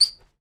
Foley Sports / Tennis / Court Squeak Generic B.wav
Court Squeak Generic B.wav